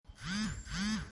phone-vibration-96623.mp3